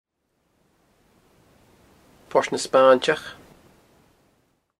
The name as it is spoken in Applecross, Port na Spainntich, is grammatically incorrect.